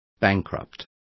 Also find out how fallido is pronounced correctly.